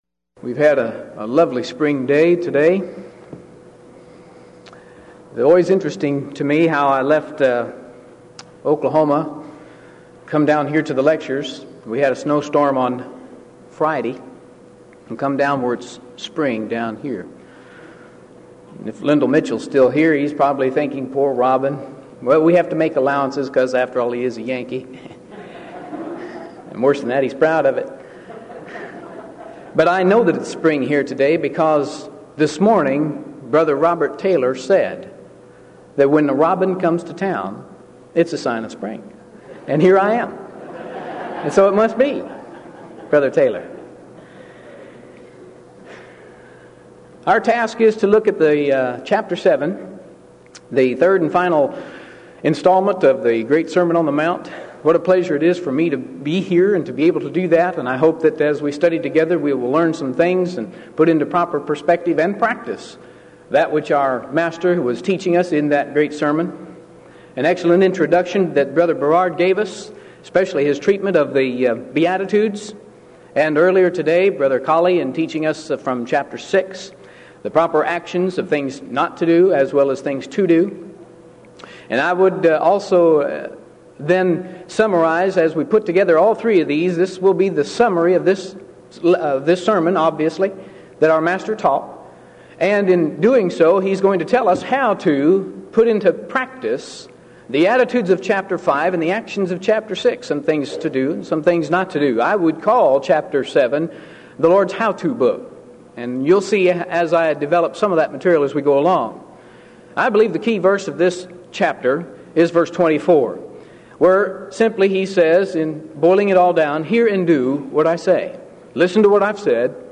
Event: 1995 Denton Lectures
lecture